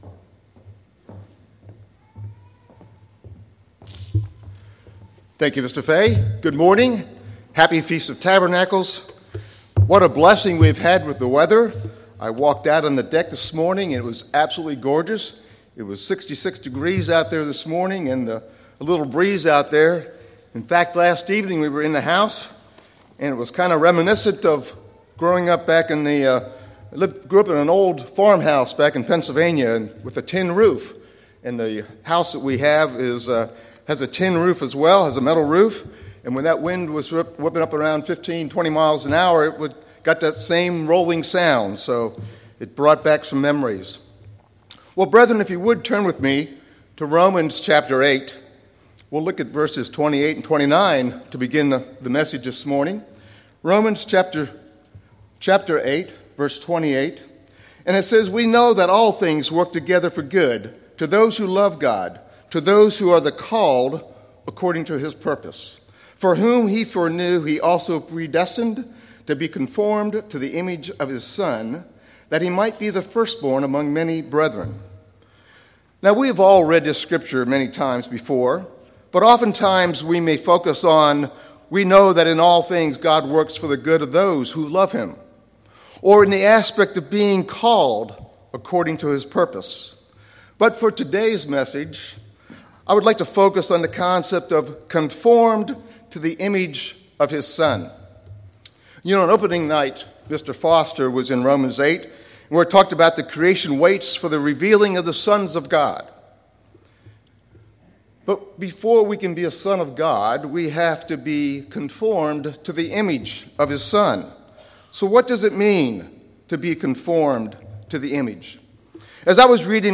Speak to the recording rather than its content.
This sermon was given at the New Braunfels, Texas 2021 Feast site.